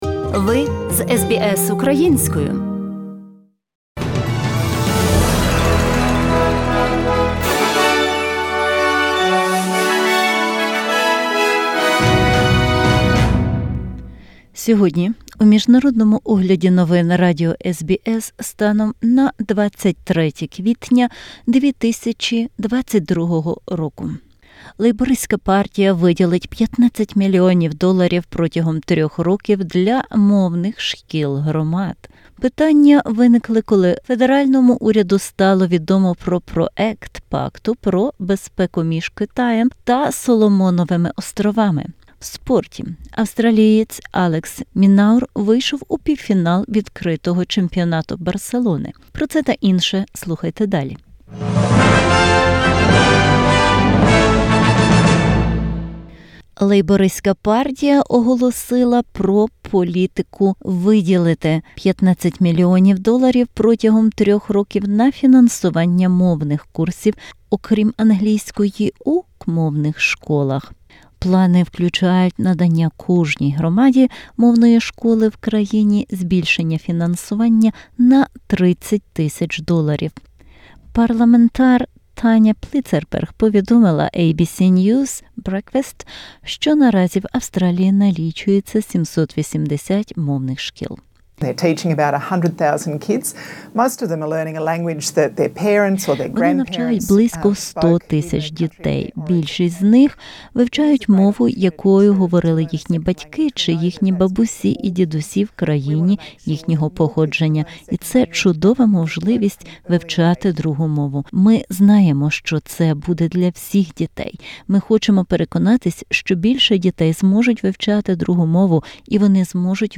In this bulletin, The Labor Party to commit $15 million over three years for community language schools... Questions raised over when the federal government became aware of a draft security pact between China and the Solomon Islands. In sport, Australia's Alex de Minaur through to the Barcelona Open semi-finals.